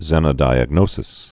(zĕnə-dīəg-nōsĭs, zēnə-)